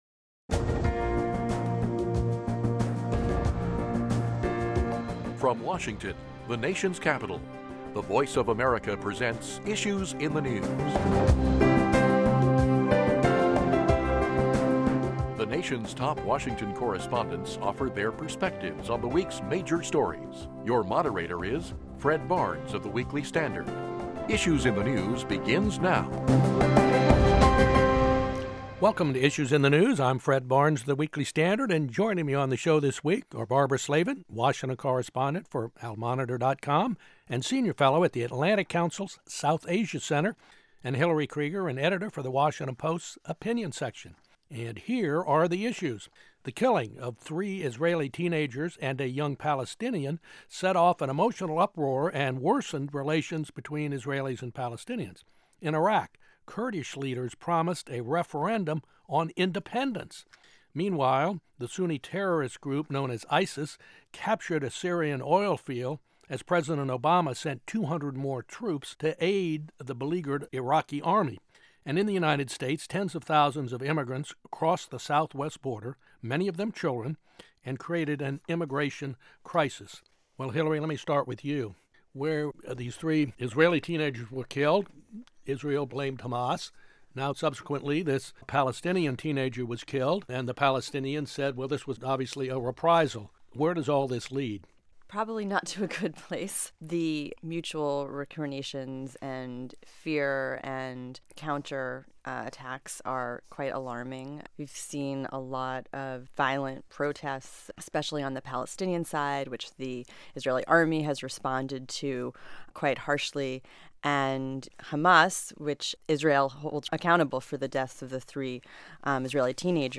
Moderator Fred Barnes, Executive Editor for The Weekly Standard